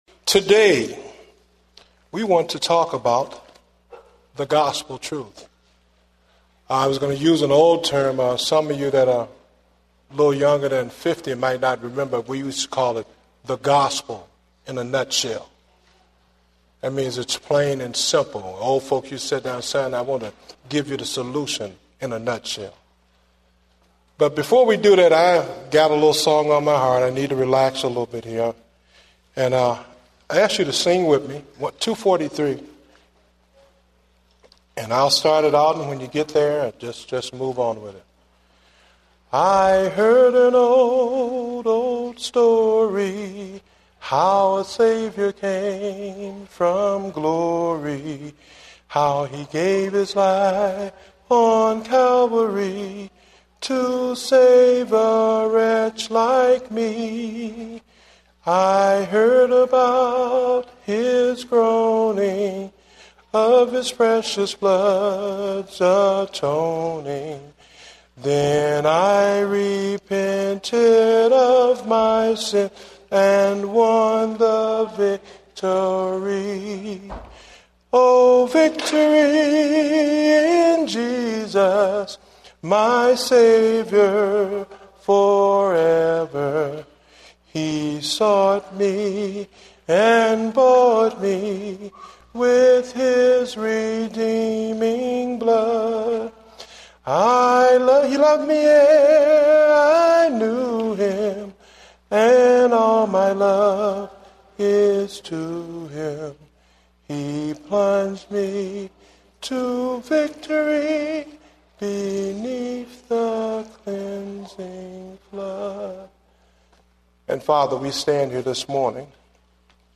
Date: September 12, 2010 (Morning Service)